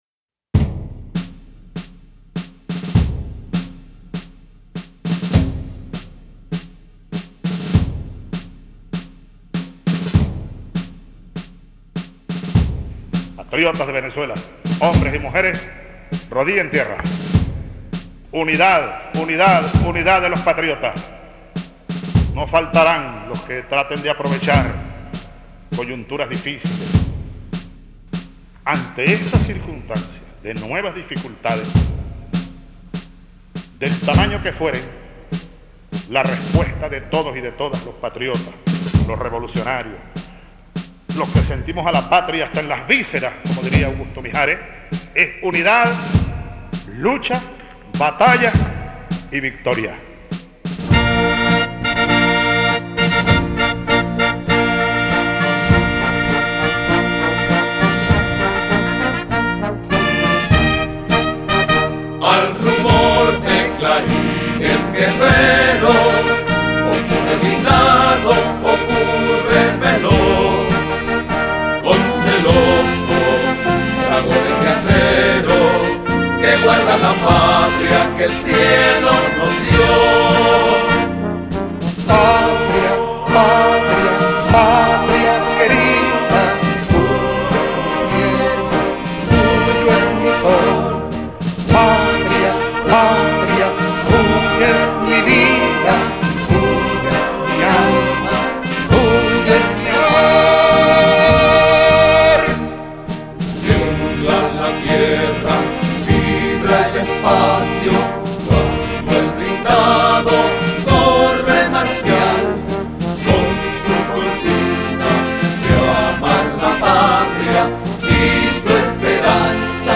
Himno